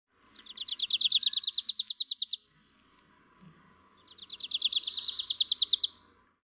Dendroica graciae (Grace's warbler)
This example of the same species was recorded 100 mi (60 KM) away on Mingus Mountain, Arizona, 6/6/99.